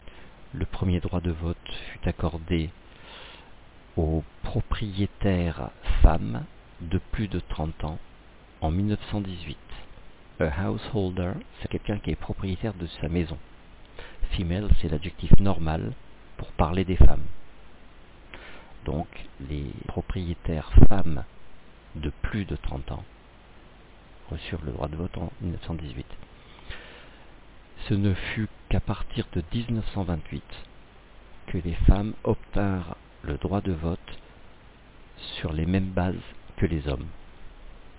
J'ai enregistré une traduction du texte "Suffragettes", à la demande d'une élève.